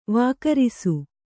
♪ vākarisi